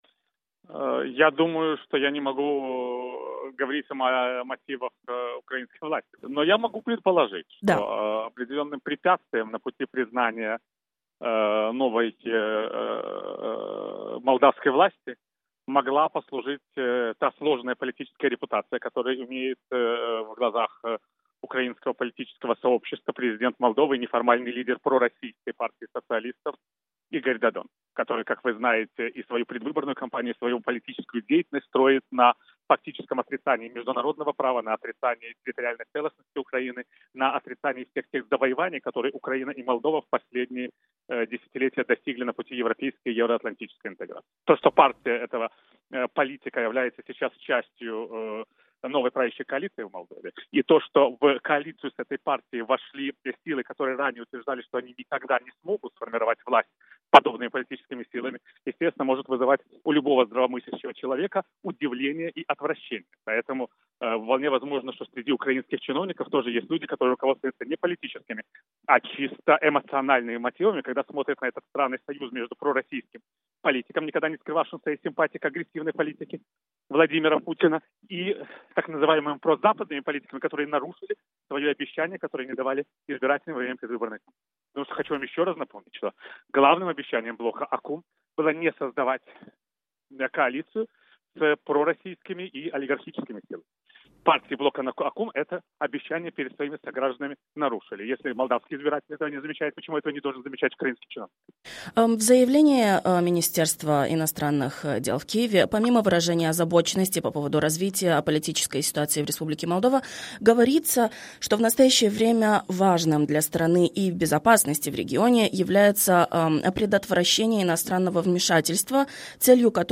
Интервью Виталия Портникова кишиневскому бюро Свободной Европы